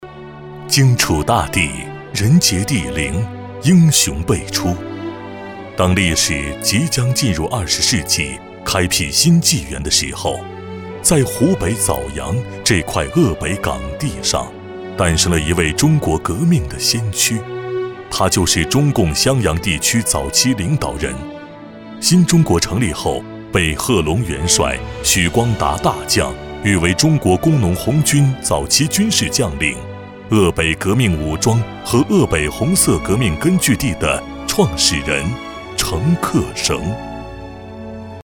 深情缓慢 企业专题,人物专题,医疗专题,学校专题,产品解说,警示教育,规划总结配音
大气磁性青年配音员，四川卫视等多频道声音代言，擅长不同题材配音，时间稳定。